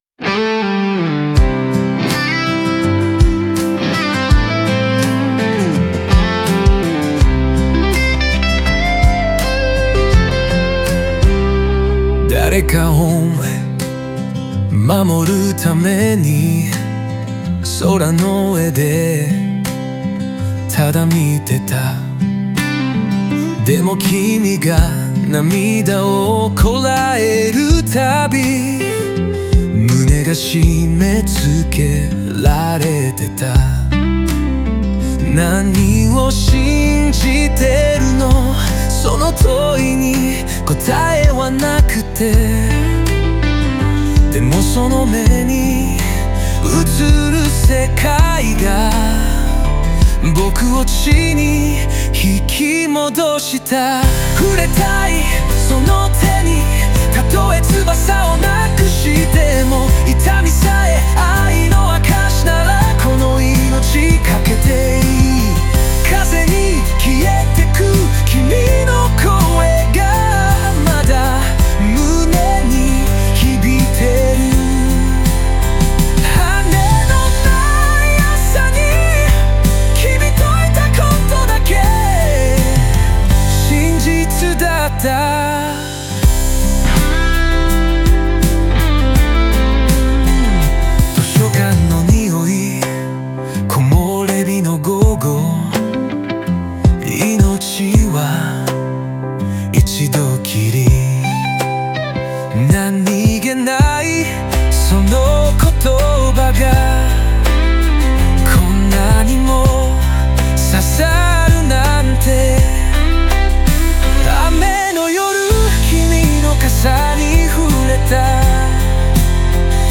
オリジナル曲♪
自然や音、風景を比喩に用い、魂の変化と再生の過程を、静と動の音楽展開と共に表現しています。